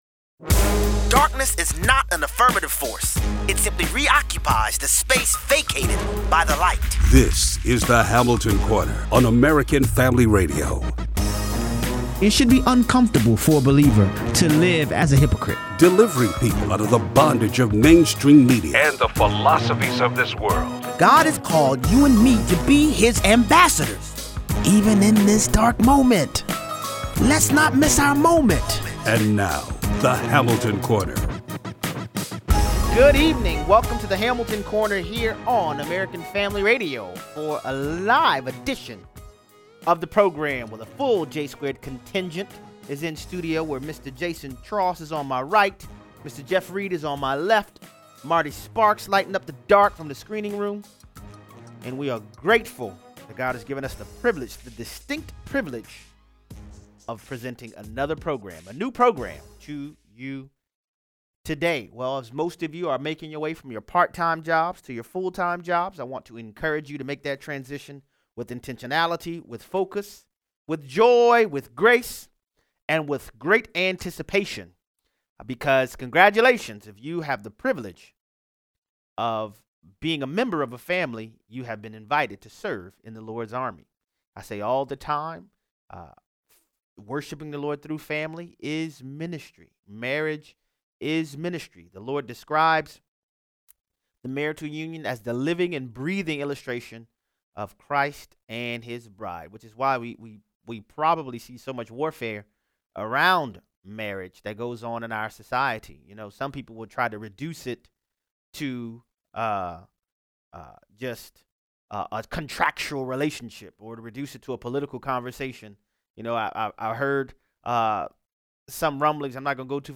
Chinese schools are urging children to report Christian relatives to the authorities. Callers weigh in.